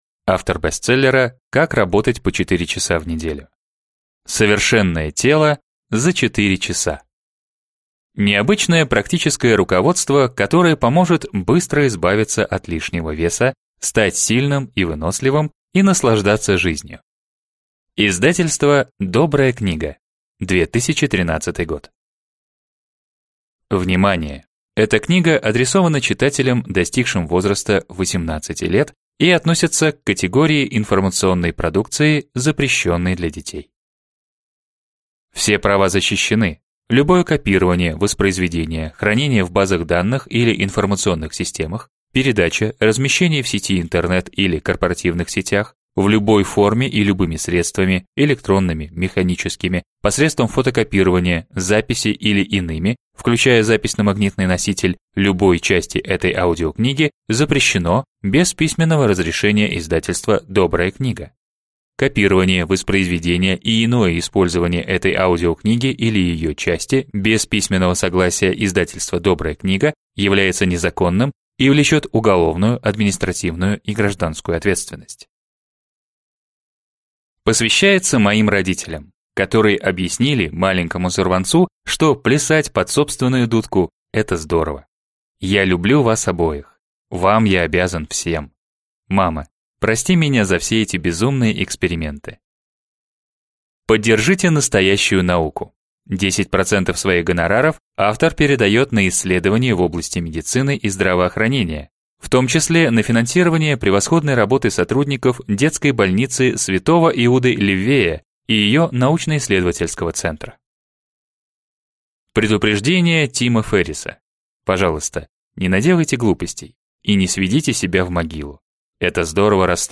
Аудиокнига Совершенное тело за 4 часа.